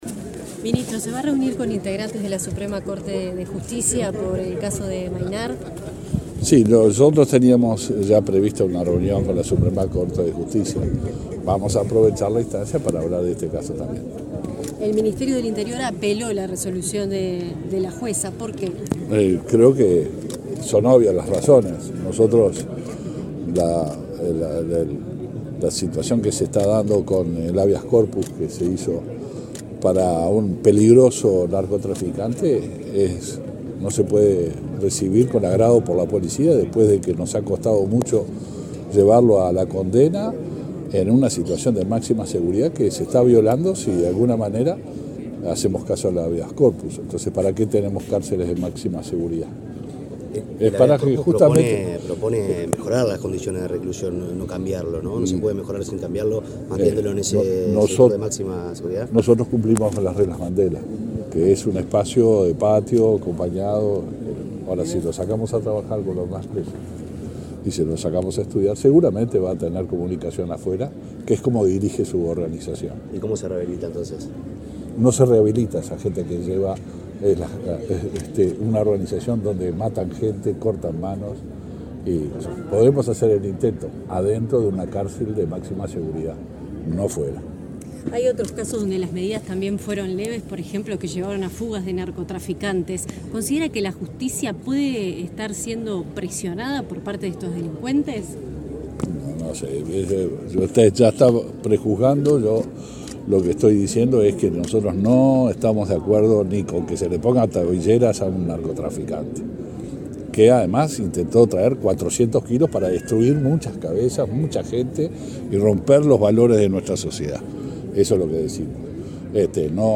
El ministro del Interior, Luis Alberto Heber, fue entrevistado para medios periodísticos luego de participar de la ceremonia de egreso de una nueva